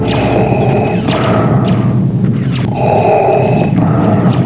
darthbreathing.wav